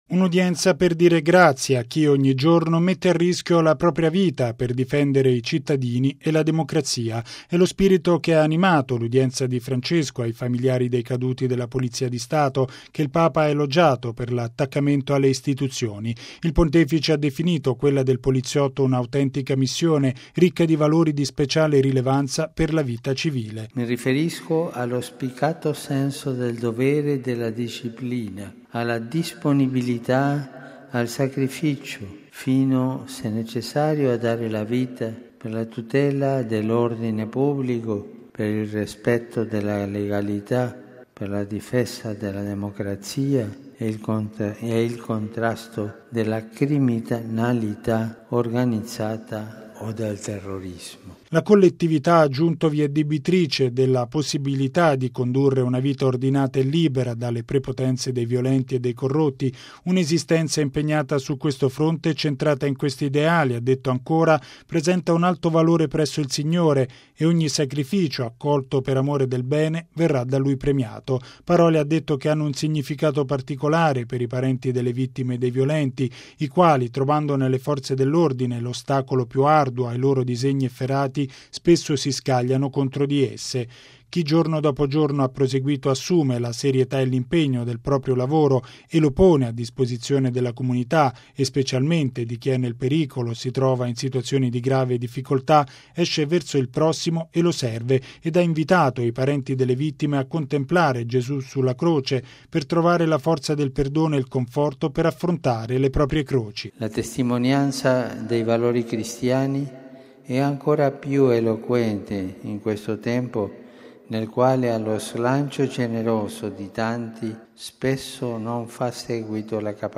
Logo 50 Radiogiornale Radio Vaticana